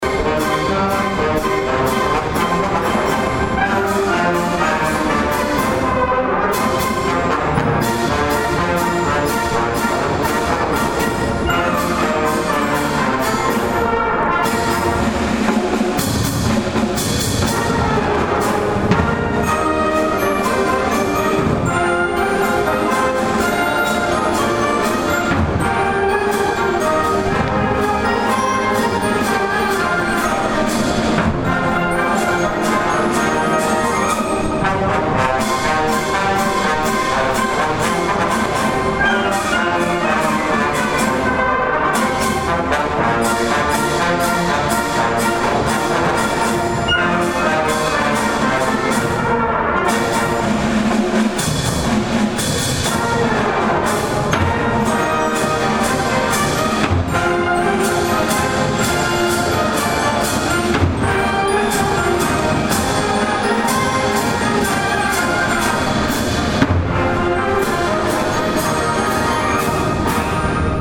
The Karl L. King Municipal Band of Fort Dodge, Iowa
the performance to be tranferred to Decker Auditorium at Iowa Central Community College.
The second number on the evening program was the march King of Fort Dodge